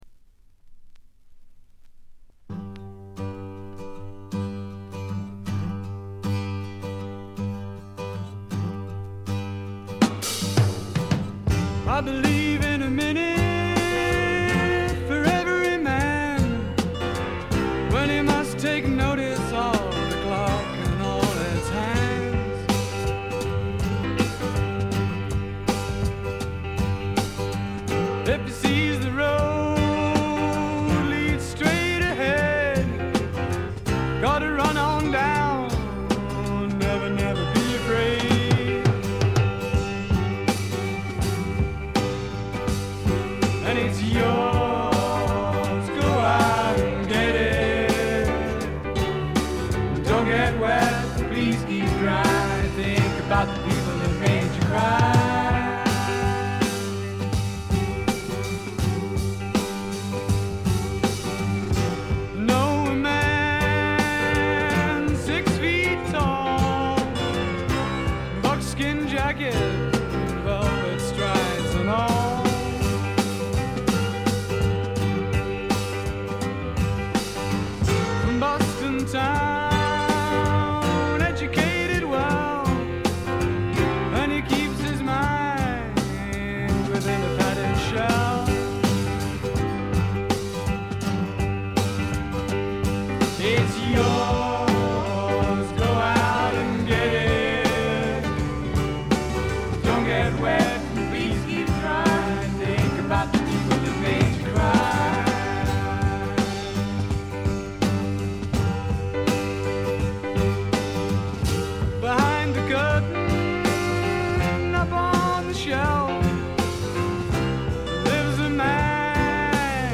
ほとんどノイズ感無し。
音の方はウッドストック・サウンドに英国的な香りが漂ってくるという、この筋の方にはたまらないものに仕上がっています。
試聴曲は現品からの取り込み音源です。